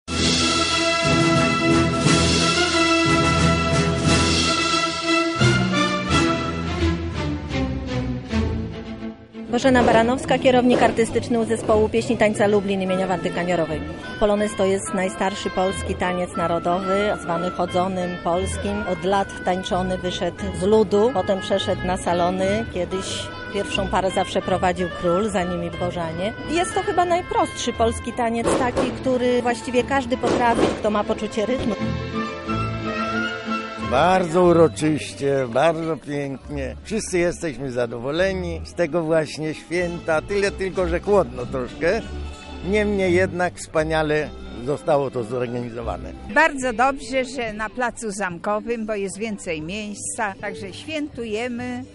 Uroczysty polonez na Placu Zamkowym
Na Wzgórzu Zamkowym wybrzmiał także narodowy polonez.